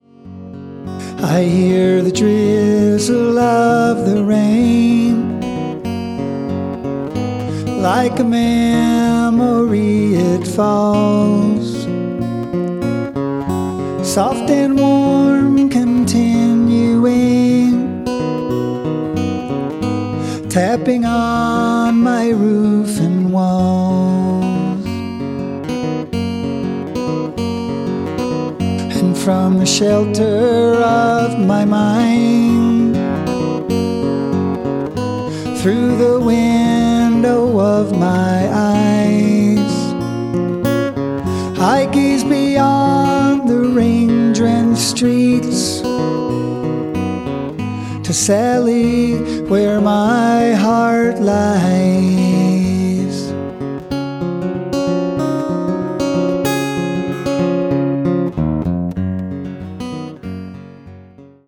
I decided to record these songs the way they were written.